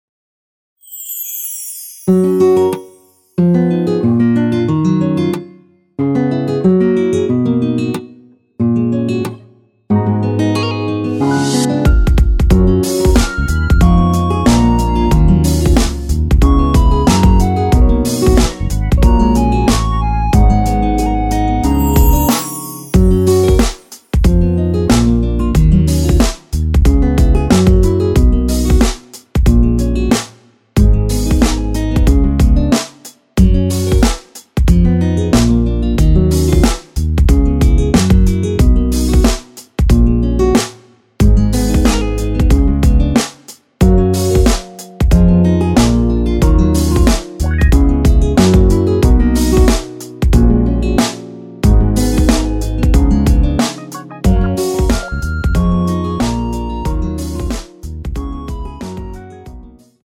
엔딩이 페이드 아웃이라 라이브 하시기 좋게 엔딩을 만들어 놓았습니다.
F#
◈ 곡명 옆 (-1)은 반음 내림, (+1)은 반음 올림 입니다.
앞부분30초, 뒷부분30초씩 편집해서 올려 드리고 있습니다.